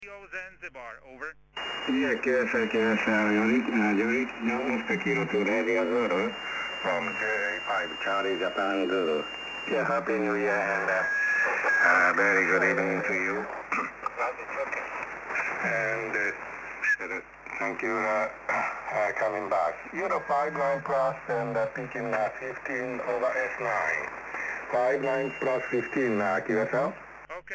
Jak posloucháte DXy v pásmu 80m?